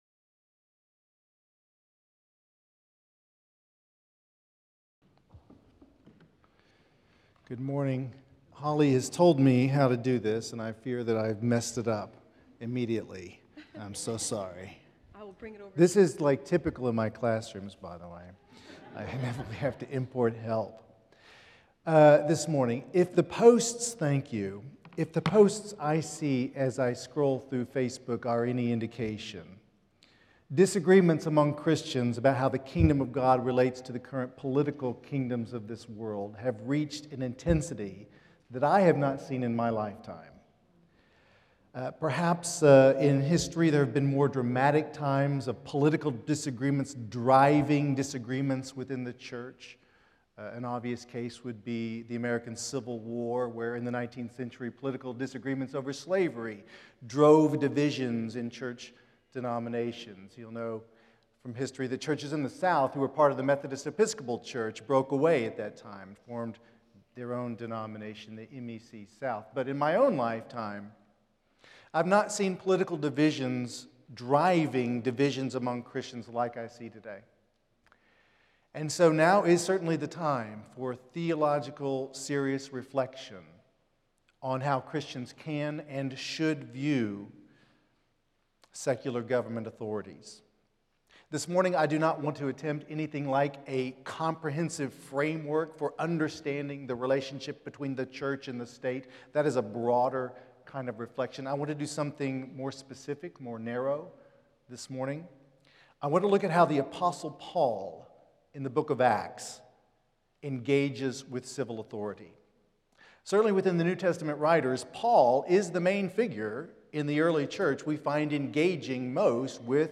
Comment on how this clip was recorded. recorded on Thursday, February 20, 2025 at Asbury Theological Seminary's Kentucky campus<